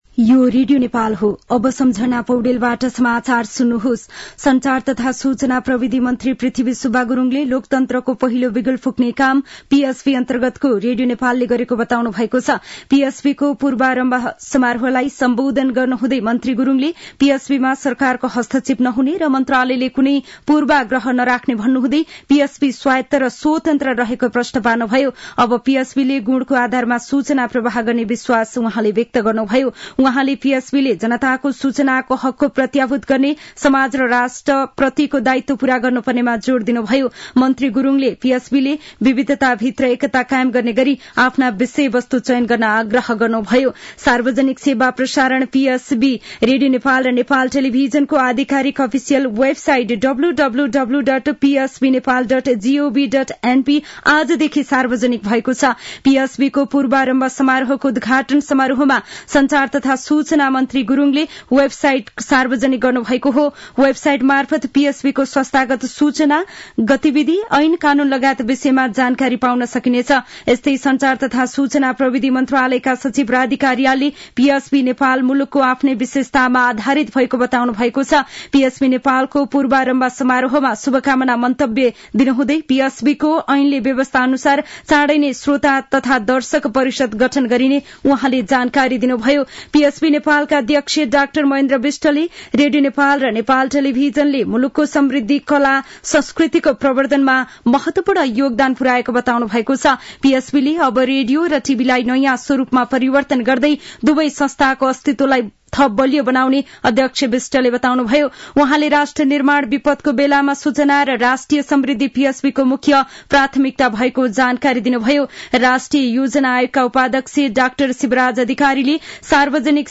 दिउँसो १ बजेको नेपाली समाचार : २ माघ , २०८१
1-pm-news-1.mp3